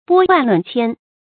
拨万论千 bō wàn lùn qiān
拨万论千发音
成语注音 ㄅㄛ ㄨㄢˋ ㄌㄨㄣˋ ㄑㄧㄢ